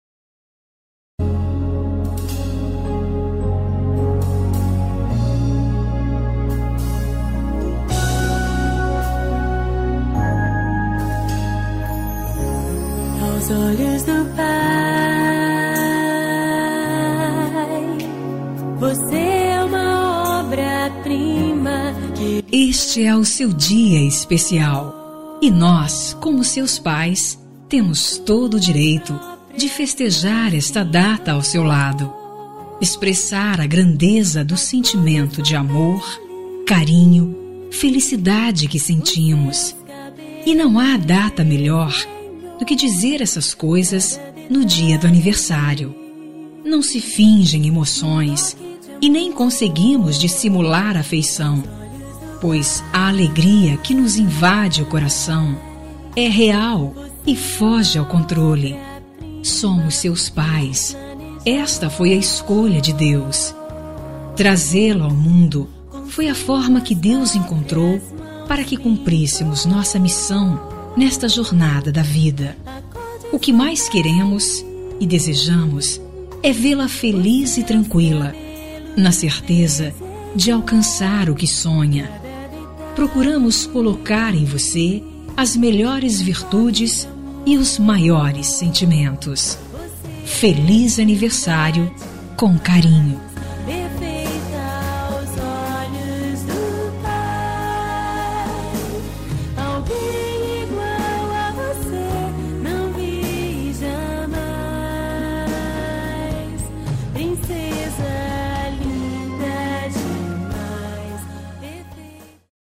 Aniversário de Filha – Voz Feminino – Cód: 5217 – Plural